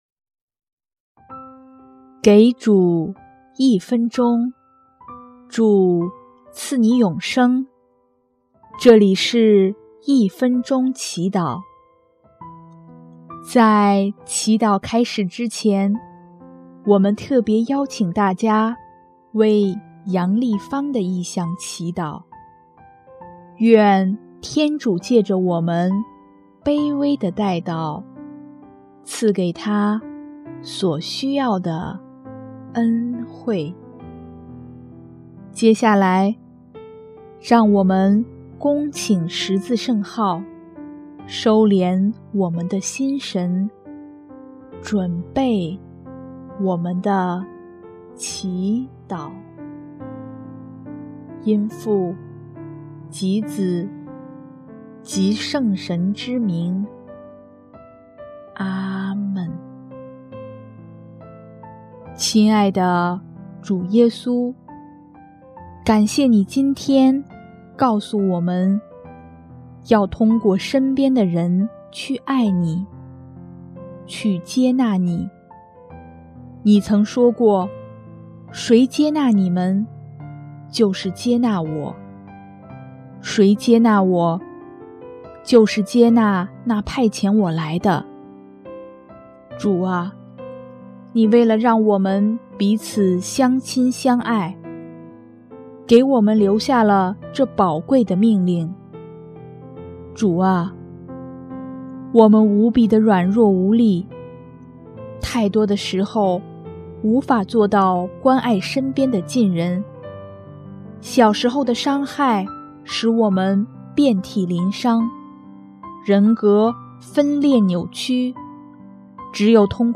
【一分钟祈祷】|7月2日 祈求治愈，能有力量关爱他人